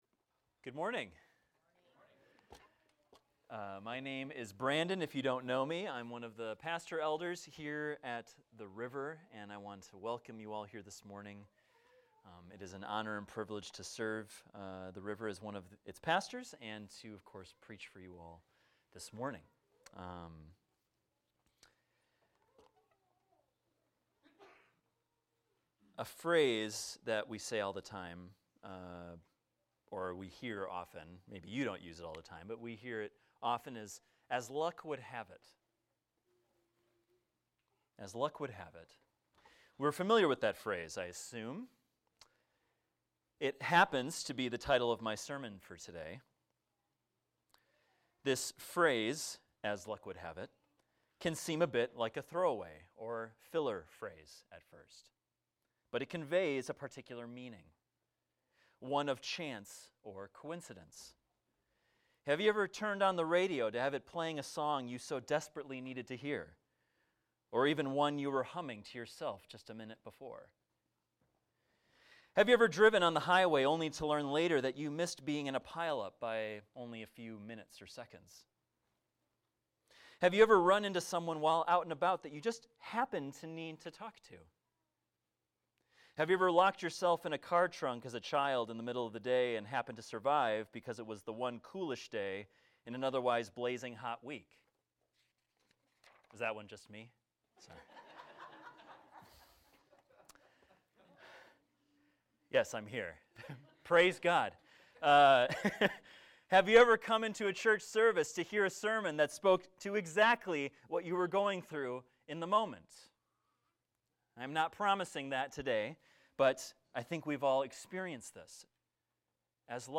A sermon on Ruth 2 titled "As Luck Would Have It"